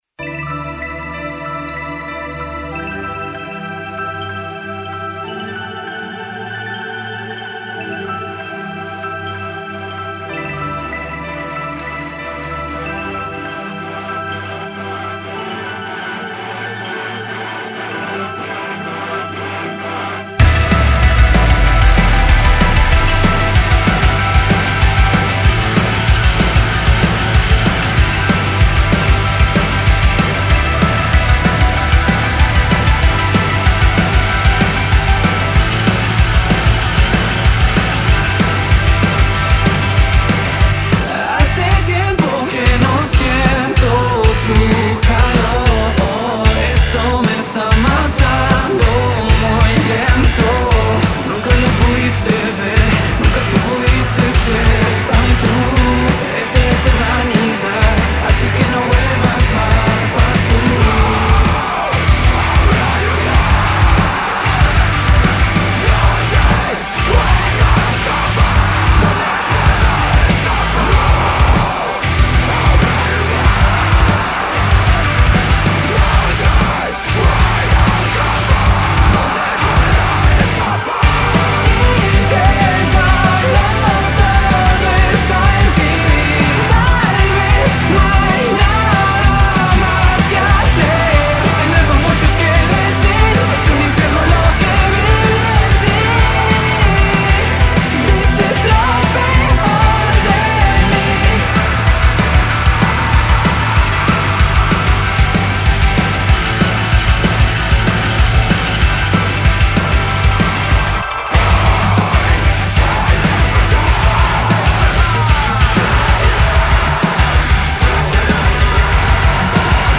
Género : Metalcore Progressives
Voz Guturales
batería